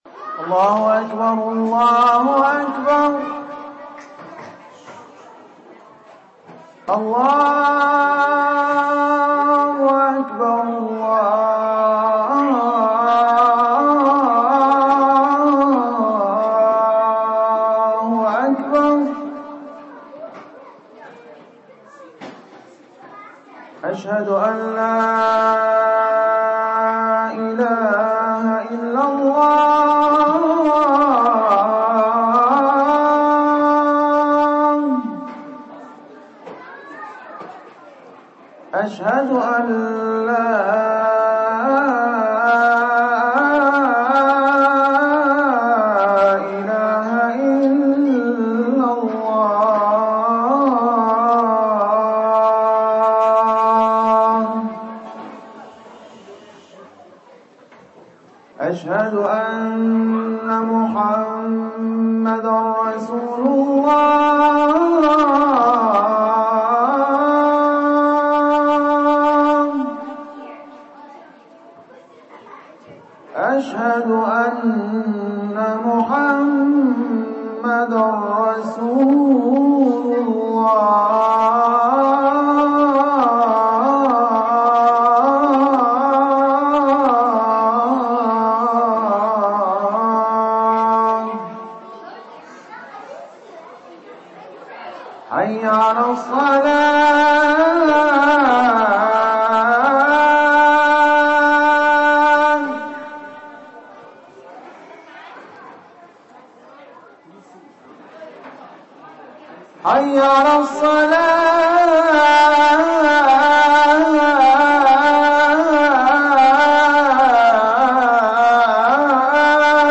أذان-15 - قسم أغســــل قلــــــبك1
أناشيد ونغمات